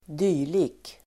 Ladda ner uttalet
Uttal: [²d'y:li(:)k]